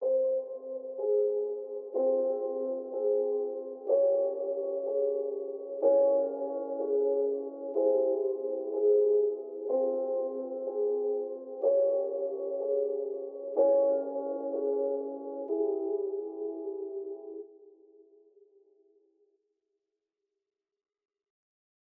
AV_Mythology_Piano_124bpm_Fmin
AV_Mythology_Piano_124bpm_Fmin.wav